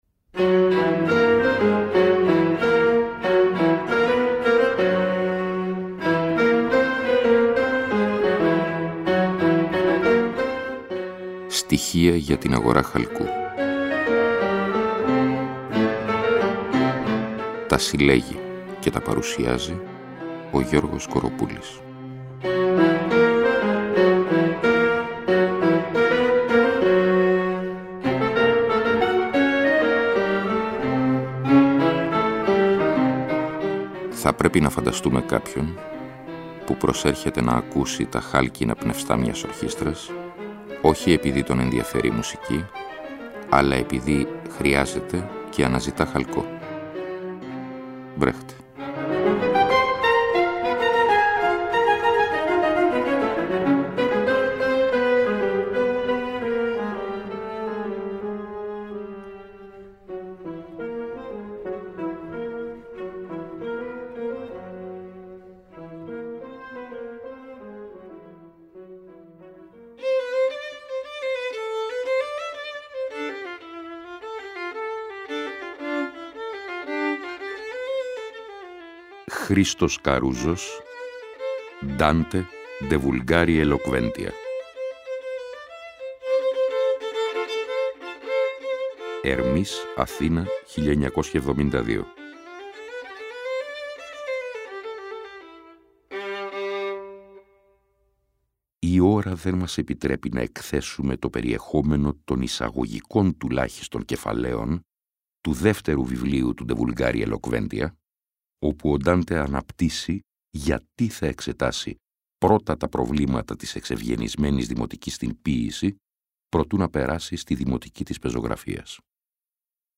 Εκπομπή λόγου.